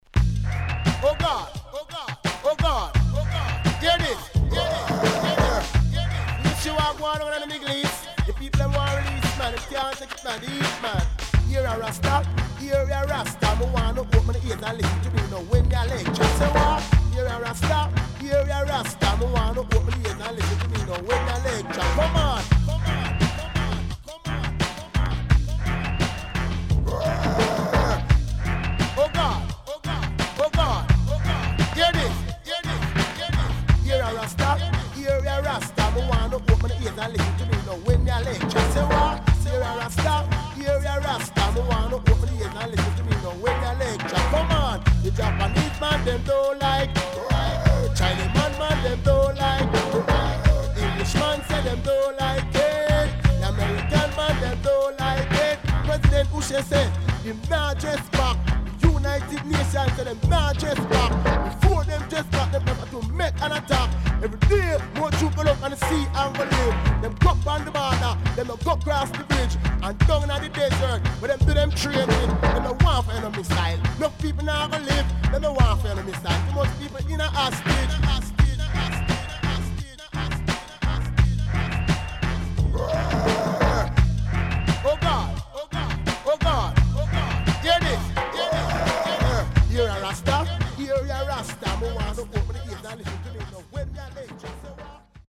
HOME > Back Order [DANCEHALL 7inch]
CONDITION SIDE A:VG(OK)〜VG+
Heavy Track
SIDE A:序盤パチノイズ1回あり、以降少しチリノイズ入ります。